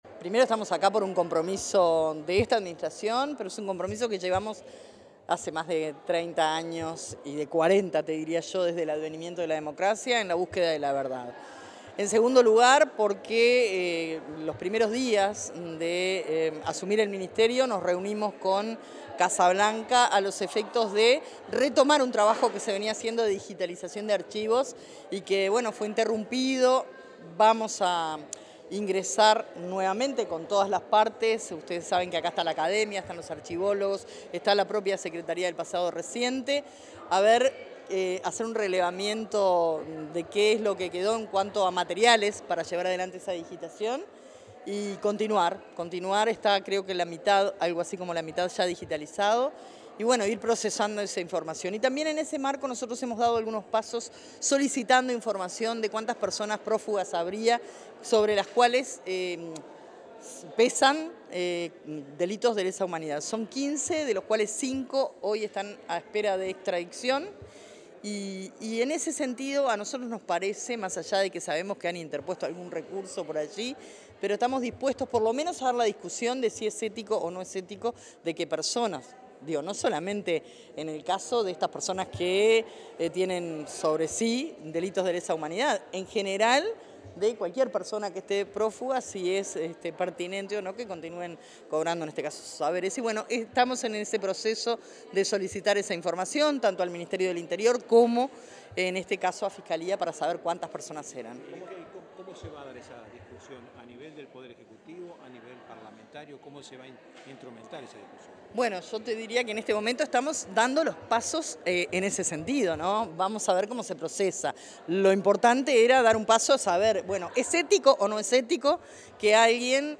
Declaraciones a la prensa de la ministra de Defensa, Sandra Lazo
La ministra de Defensa Nacional, Sandra Lazo, dialogó con la prensa luego de la presentación de los avances y lineamientos previstos para el presente